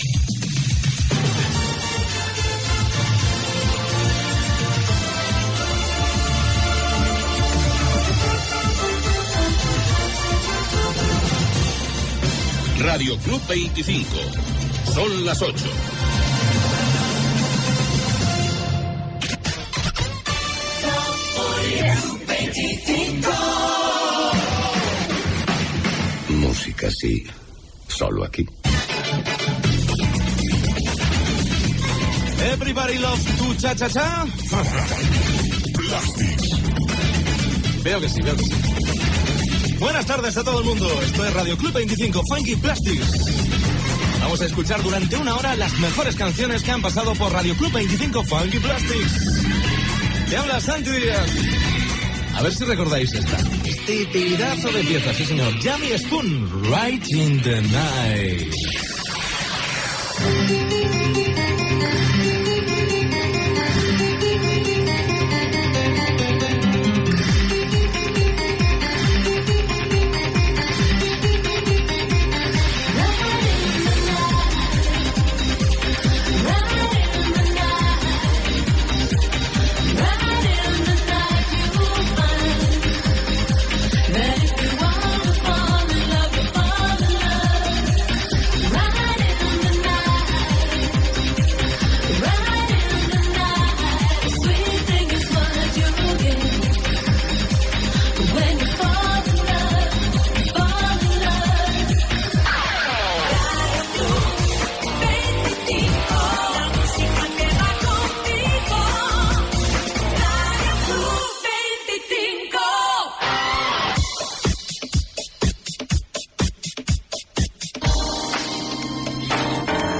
Indicatiu de l'emissora, hora.
Musical
FM